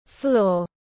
Floor /flɔːr/